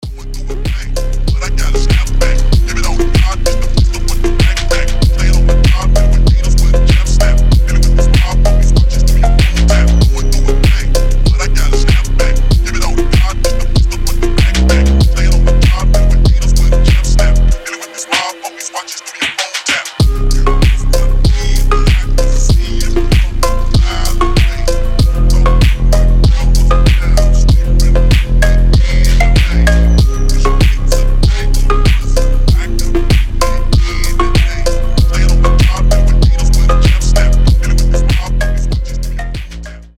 Тот самый фонк